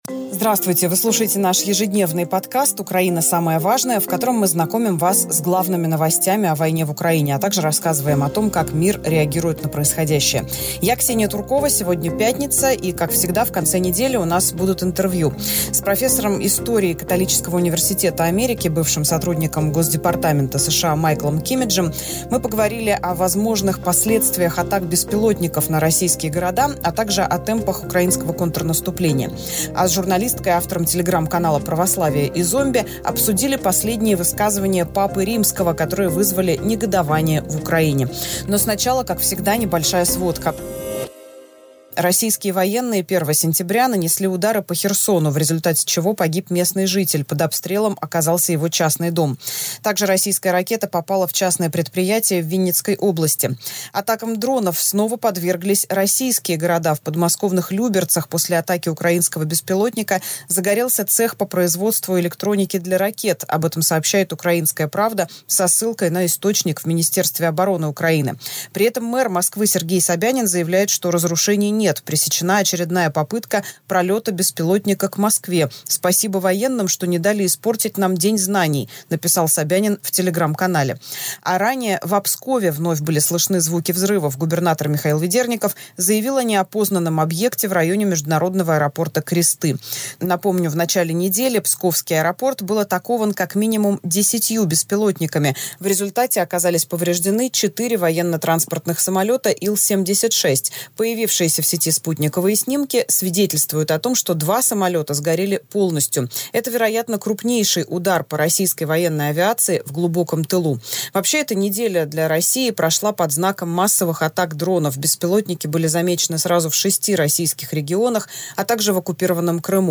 Как всегда, в пятницу наш подкаст выходит в формате интервью.